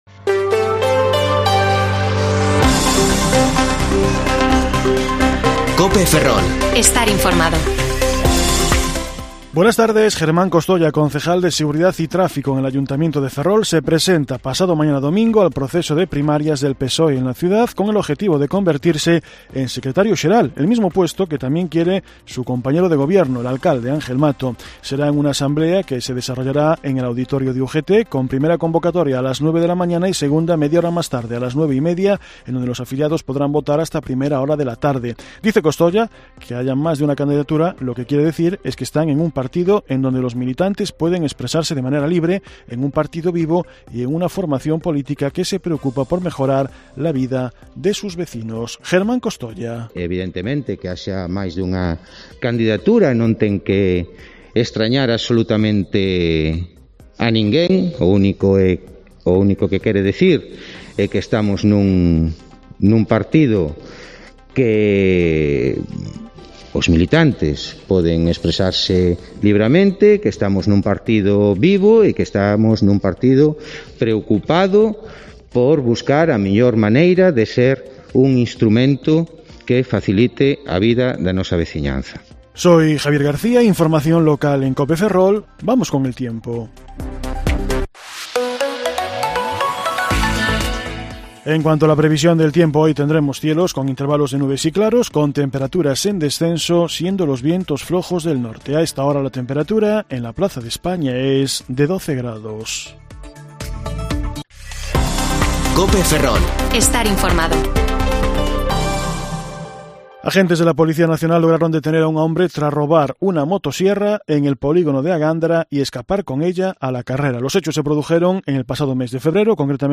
Informativo Mediodía COPE Ferrol 4/3/2022 (De 14,20 a 14,30 horas)